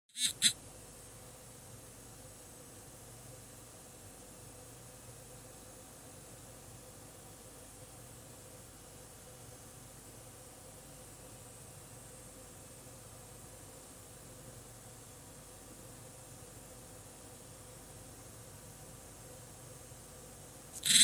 Запись с диктофона, расположенного в паре сантиметров от крыльчаток Manli GeForce GTX 1060 Gallardo, при 63%.